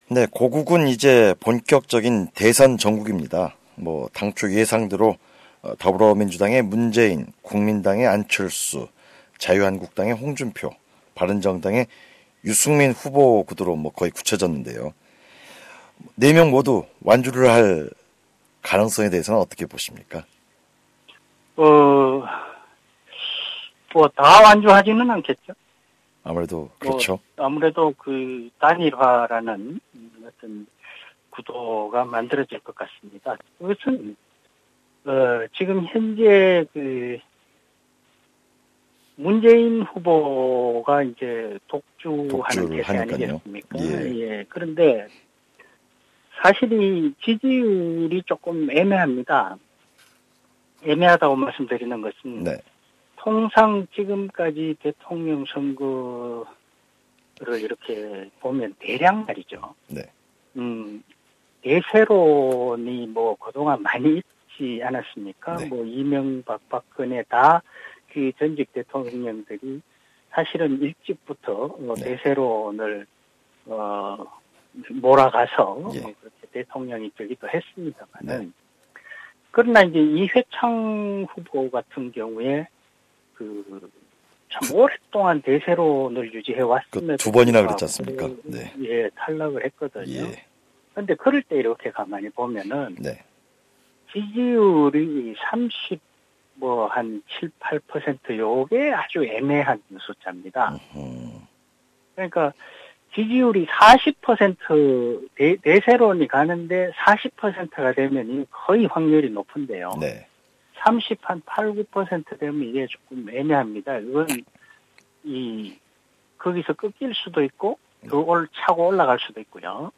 한국의 시사 평론가